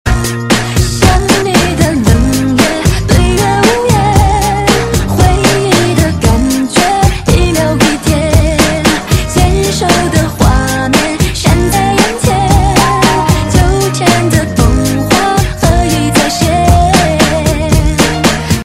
DJ铃声 大小